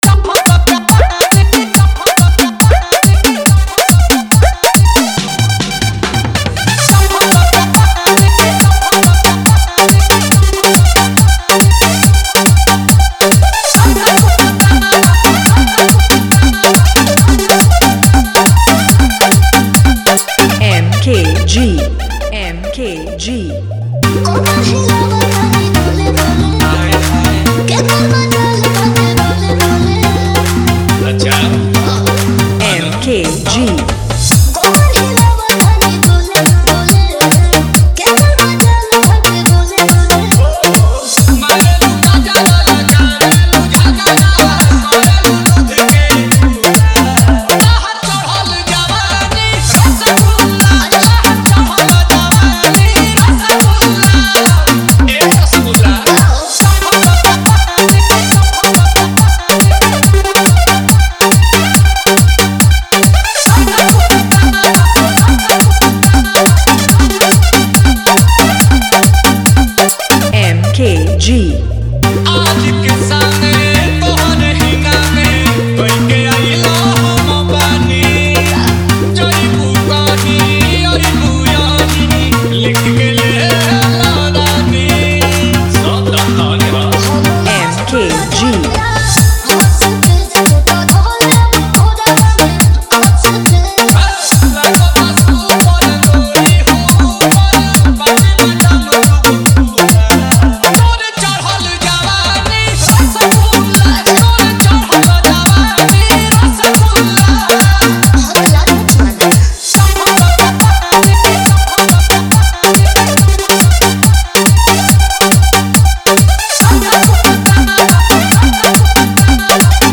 Category : Bhojpuri Dj Remix Jhanjhan Bass